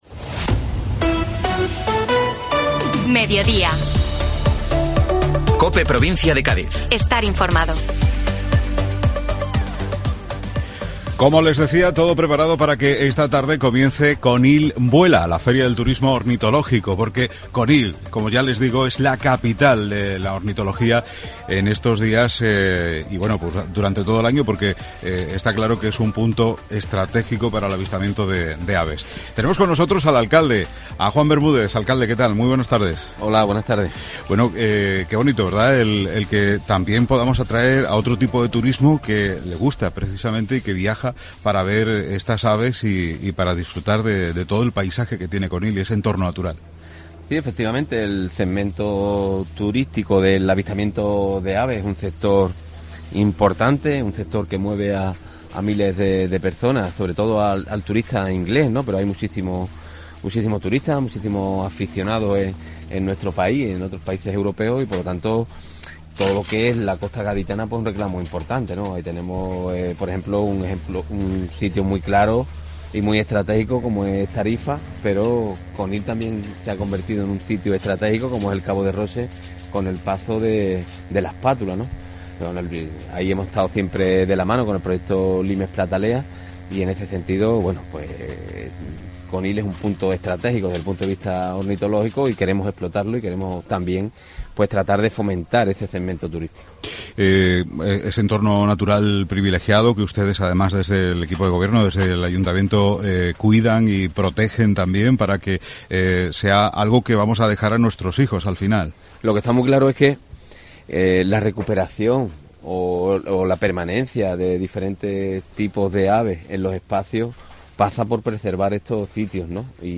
Conil Vuela, Mediodía COPE desde la primera feria ornitológica de Conil.